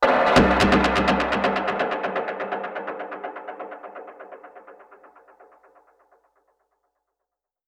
Index of /musicradar/dub-percussion-samples/125bpm
DPFX_PercHit_B_125-02.wav